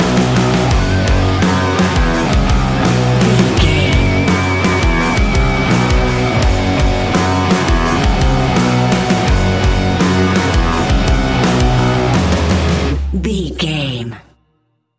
Ionian/Major
F♯
hard rock
heavy metal
instrumentals